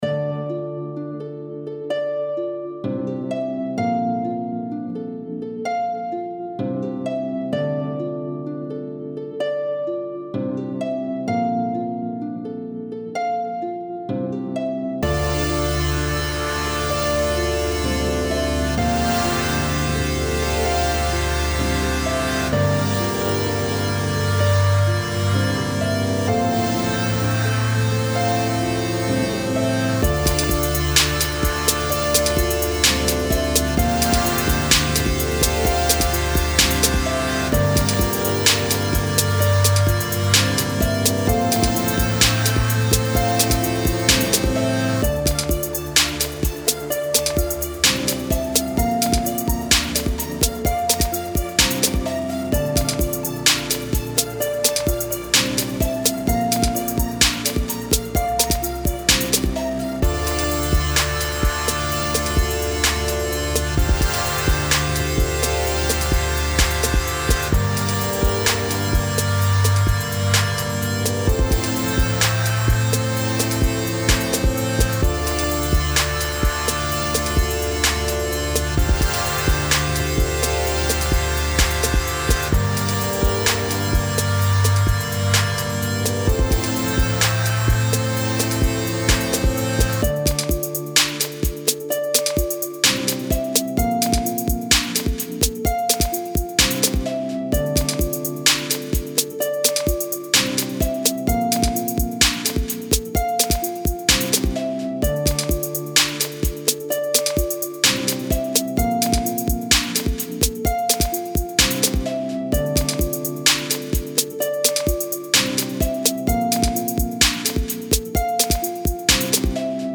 INSTRUMENTAL PRODUCTION